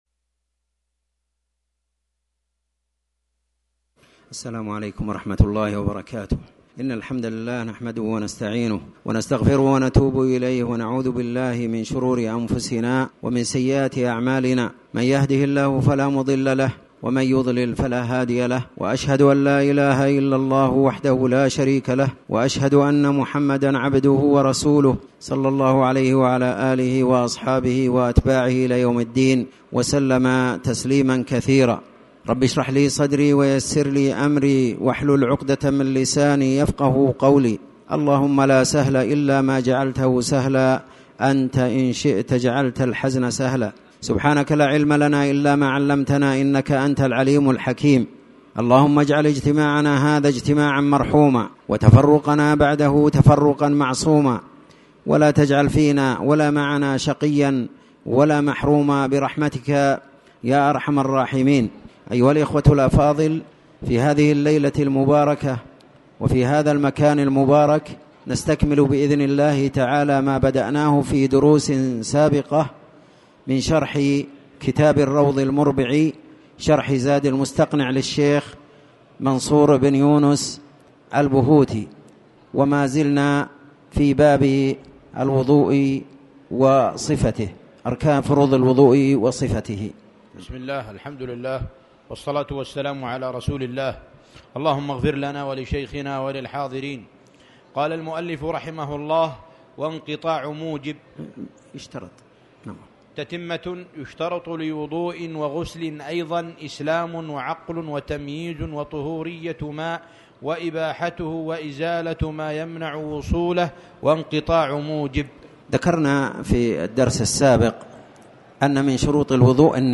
تاريخ النشر ٢٣ رجب ١٤٣٩ هـ المكان: المسجد الحرام الشيخ